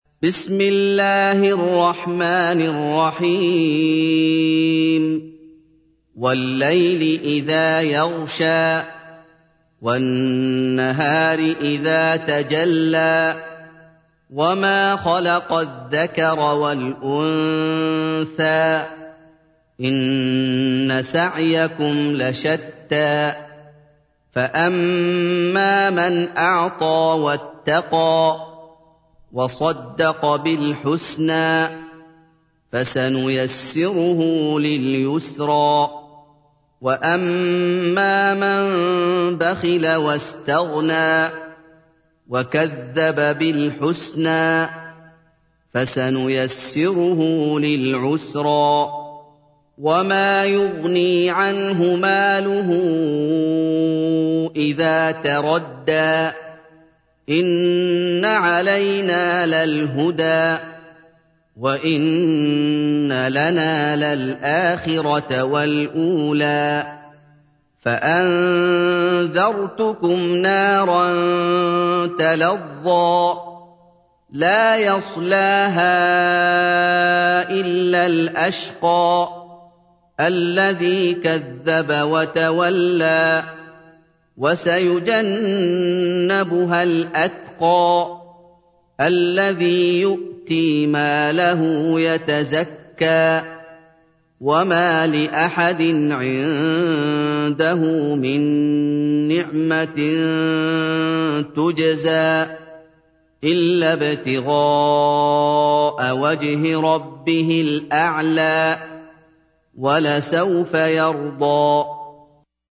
تحميل : 92. سورة الليل / القارئ محمد أيوب / القرآن الكريم / موقع يا حسين